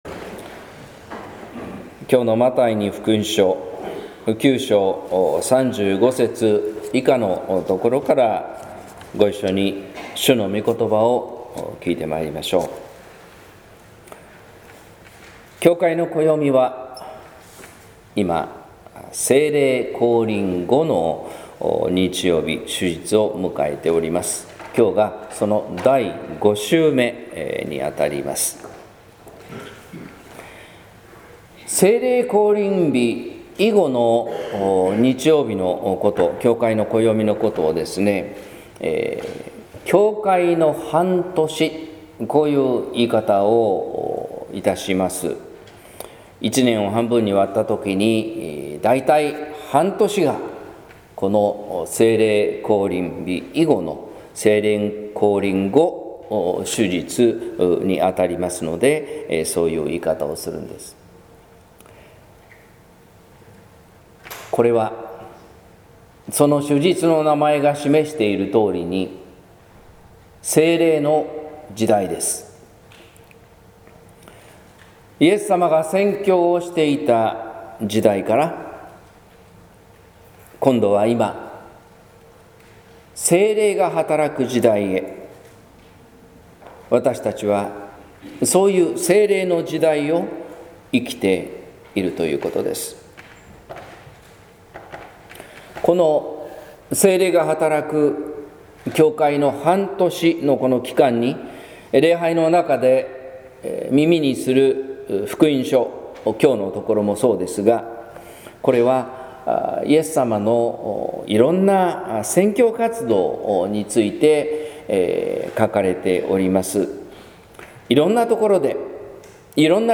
説教「心は行いに先立つ」（音声版） | 日本福音ルーテル市ヶ谷教会